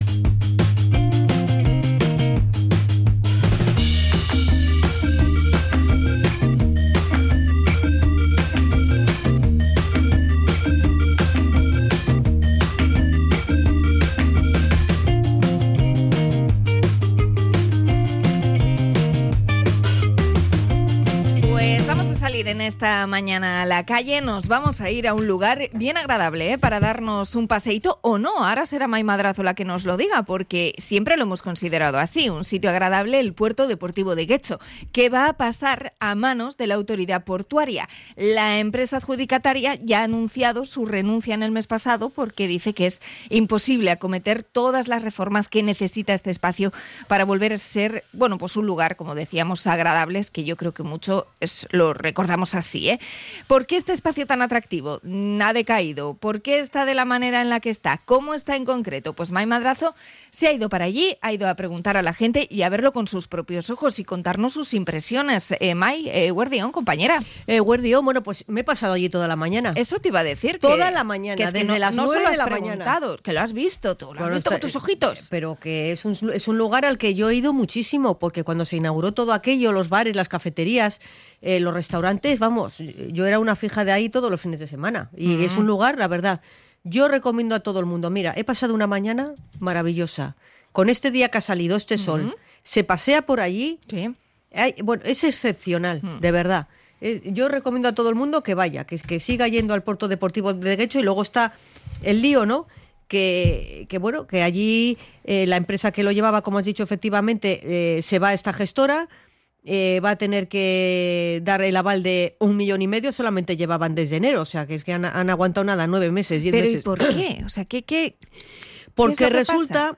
Hablamos con usuarios, comerciantes y paseantes de la zona
Salimos ala calle a comprobar el cierre de bares, tiendas y la huida de embarcaciones a Laredo y Santurtzi
REPORTAJE-PUERTO-DEPORTIVO.mp3